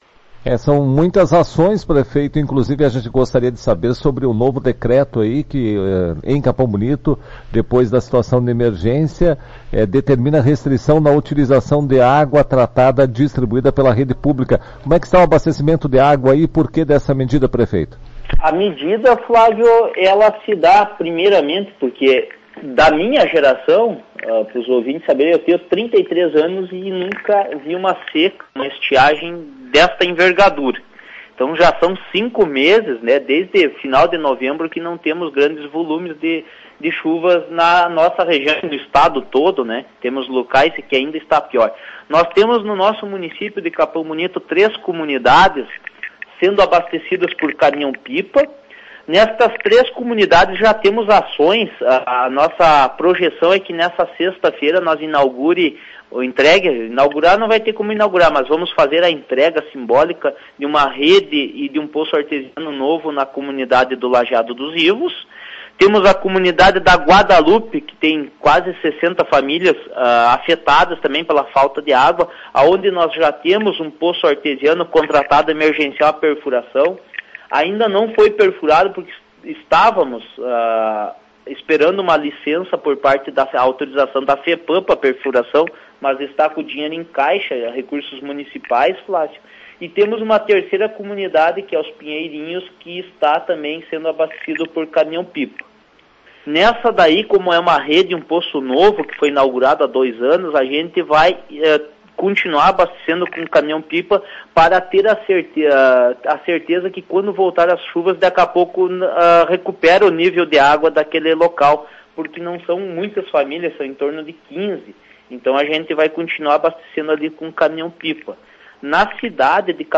Ouça entrevista do prefeito Felipe Rieth: